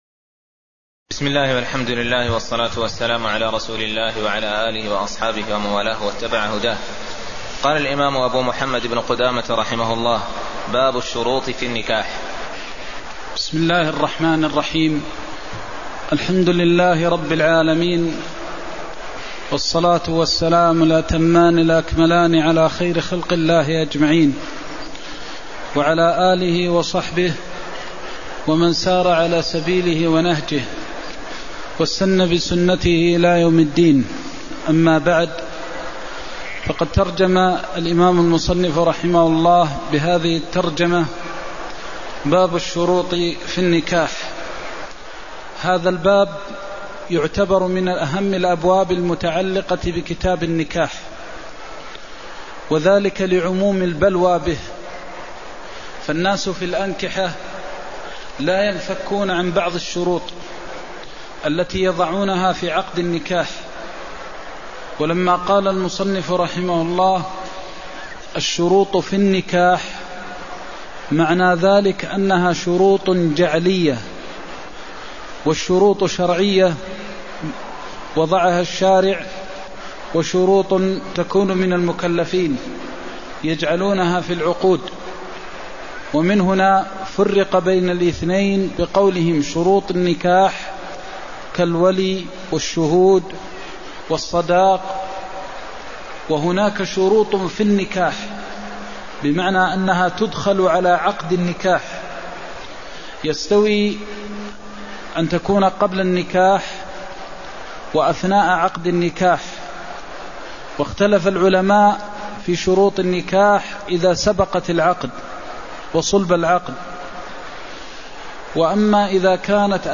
المكان: المسجد النبوي الشيخ: فضيلة الشيخ د. محمد بن محمد المختار فضيلة الشيخ د. محمد بن محمد المختار باب الشروط في النكاح (03)